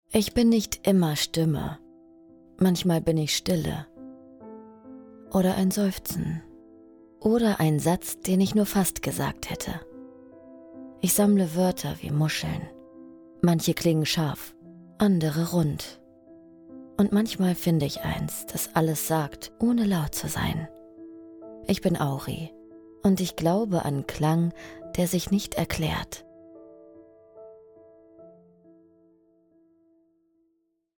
sehr variabel, hell, fein, zart, dunkel, sonor, souverän, plakativ, markant
Audiobook (Hörbuch), Doku, Commercial (Werbung), Narrative, Tale (Erzählung), Scene